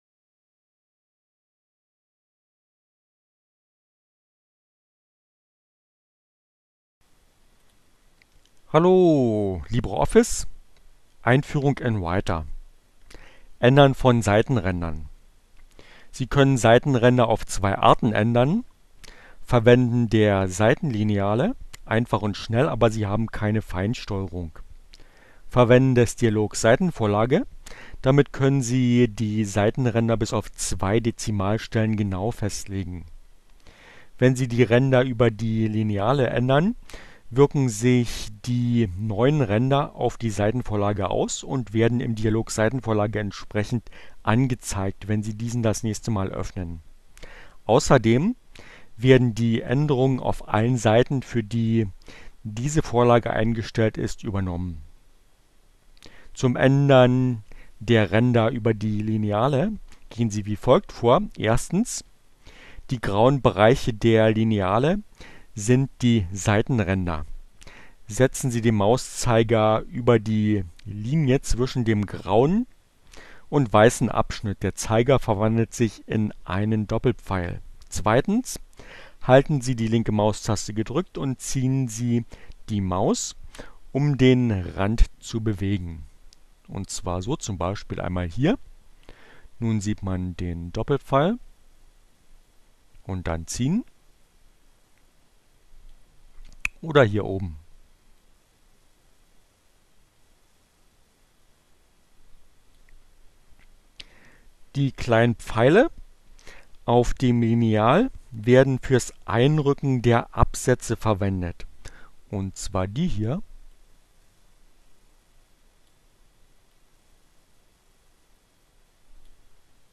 ohne Musik , screencast